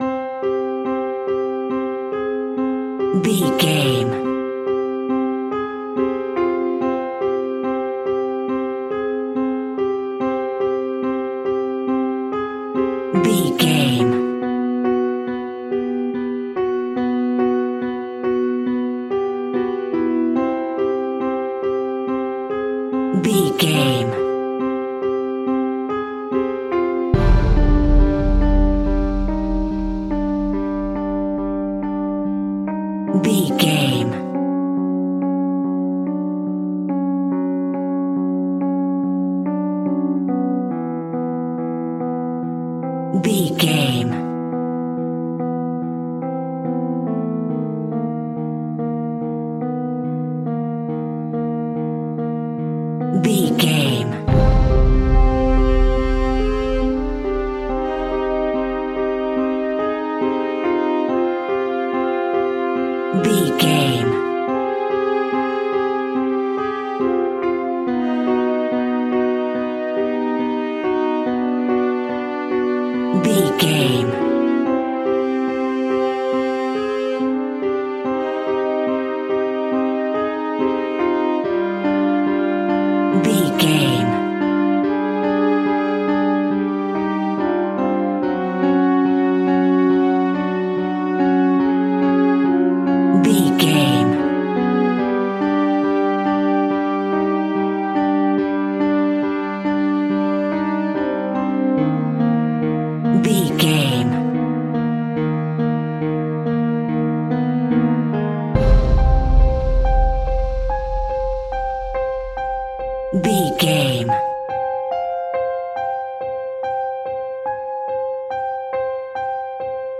Haunting Music.
Aeolian/Minor
Slow
scary
ominous
dark
suspense
eerie
piano
strings
synth
ambience
pads